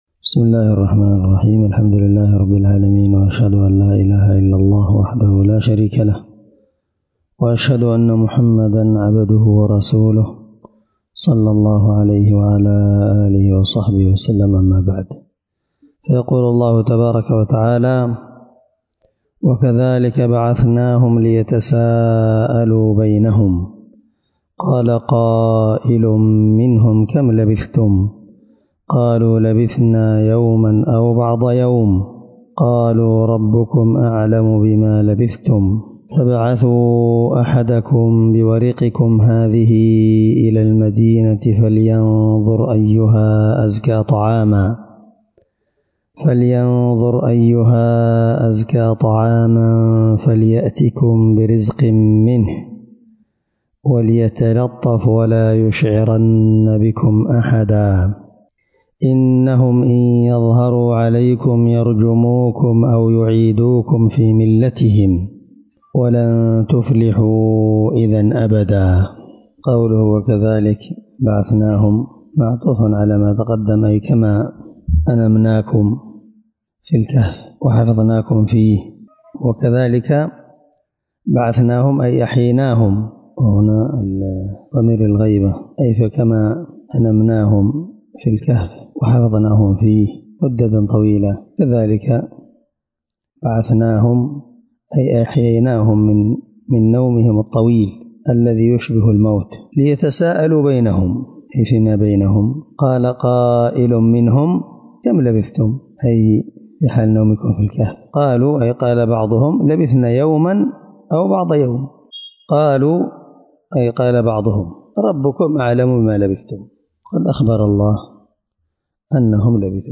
الدرس7 تفسير آية (19-20) من سورة الكهف